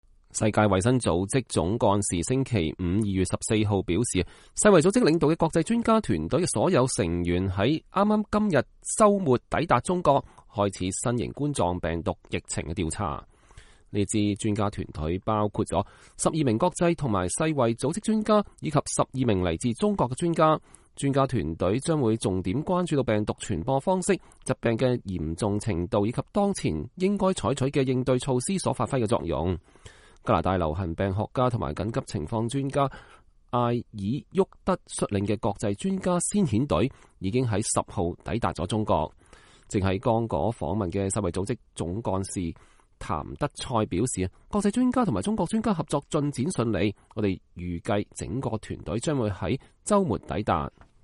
世界衛生組織總幹事譚德塞在記者會上講話。